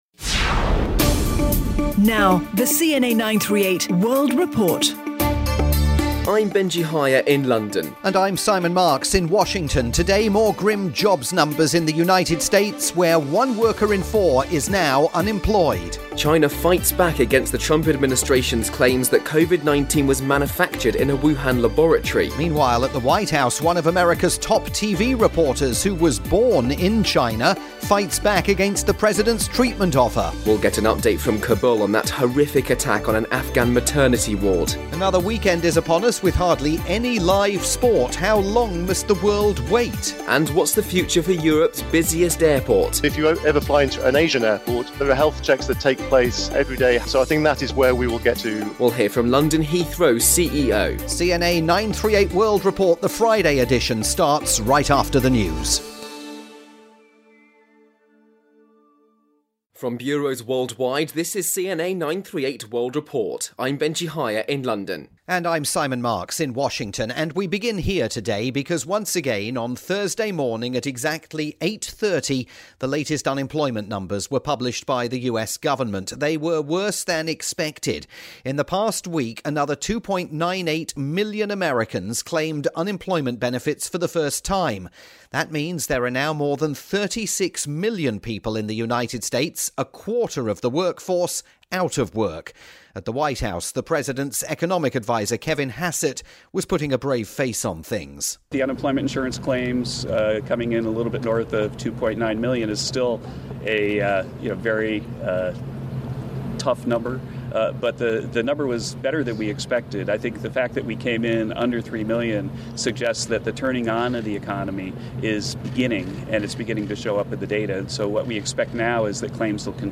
The daily radio programme produced for Singapore's newstalk station CNA 938